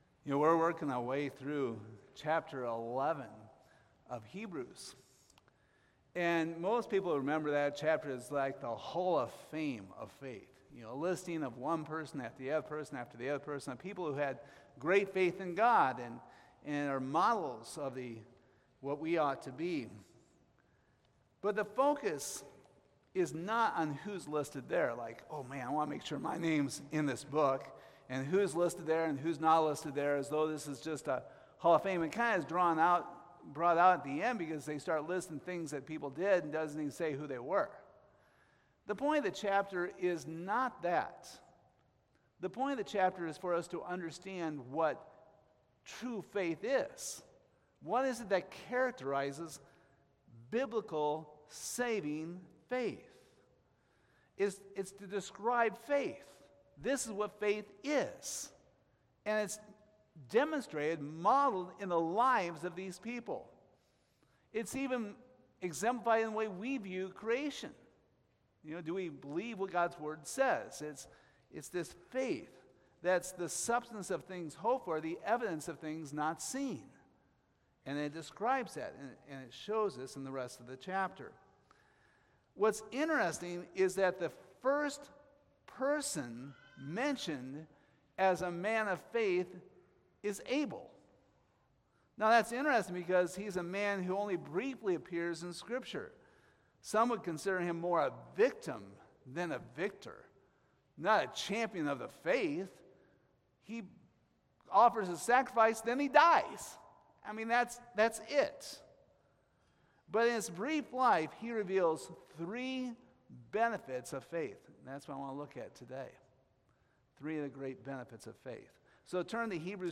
Hebrews 11:4 Service Type: Sunday Morning Is Hebrews 11 simply a chapter about the accomplishments of people?